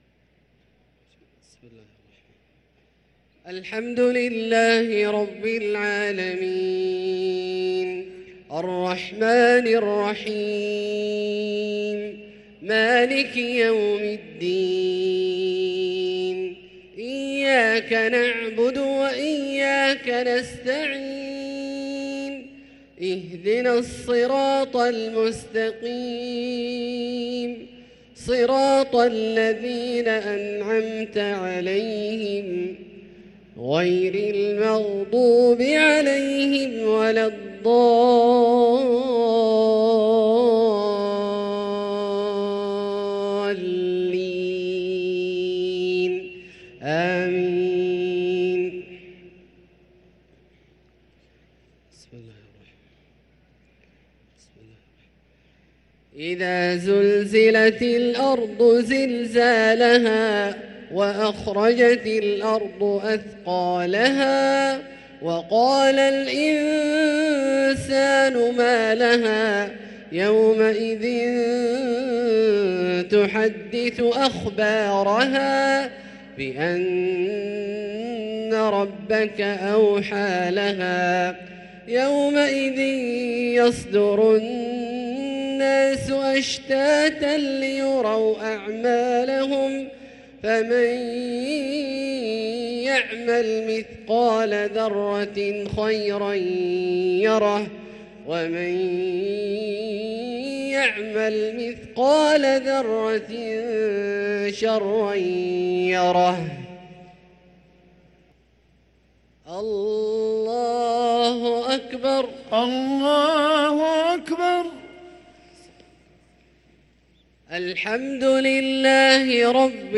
صلاة العشاء للقارئ عبدالله الجهني 7 رمضان 1444 هـ